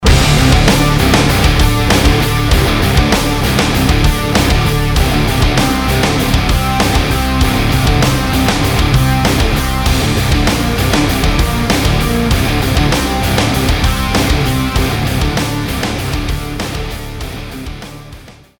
Рок проигрыш